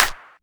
Waka SNARE ROLL PATTERN (52).wav